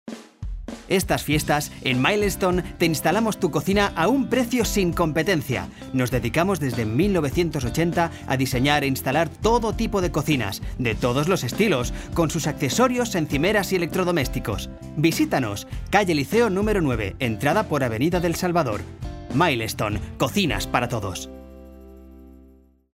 Tengo una voz joven, educada y aplicable en multitud de estilos, como publicidad, documentales, audiolibros,... Especial habilidad para interpretar personajes con voz fuera de lo común. Amplia experiencia en "listenings" de cursos de español.
kastilisch
Sprechprobe: Sonstiges (Muttersprache):